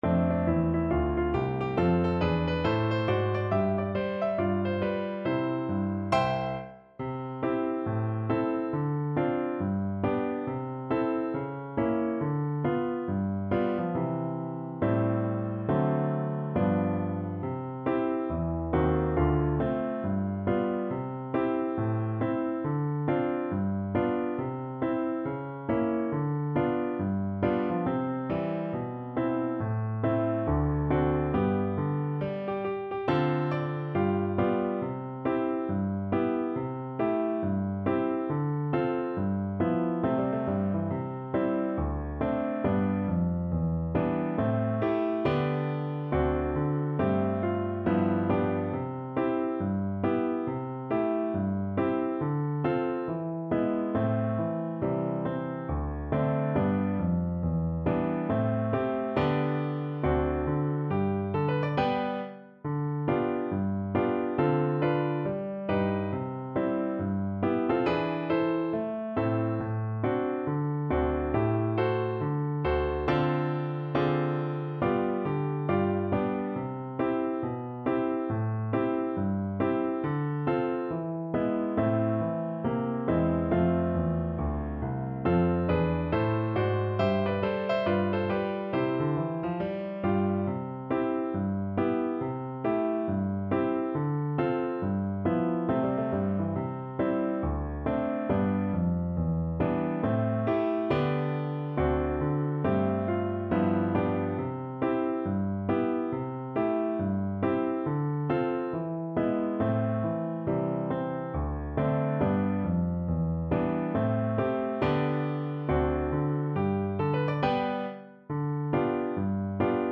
With a swing =c.69
4/4 (View more 4/4 Music)